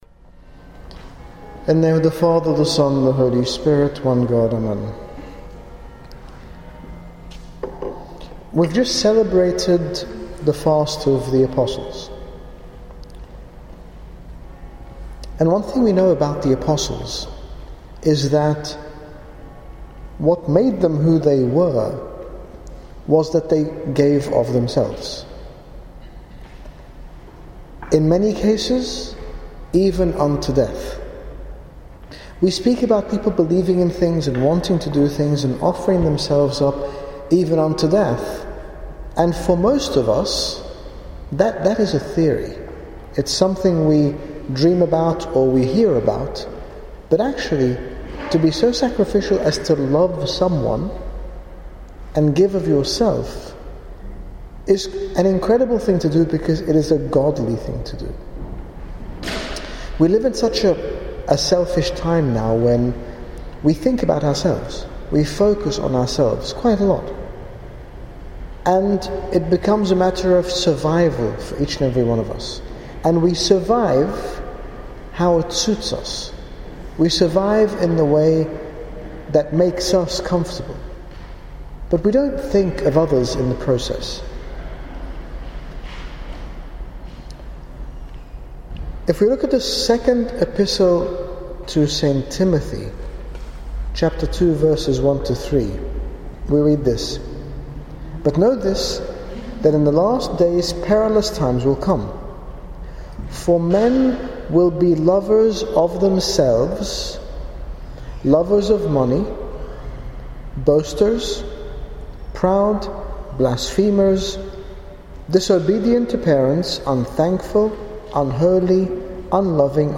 In this sermon His Grace Bishop Angaelos, General Bishop of the Coptic Orthodox Church in the United Kingdom, speaks about the Incarnation of our Lord Jesus Christ as an example of love and sacrifice for us all to follow, encouraging us to use our God-given talents for the benefit of others. Download Audio Read more about Using our talents for the sake of others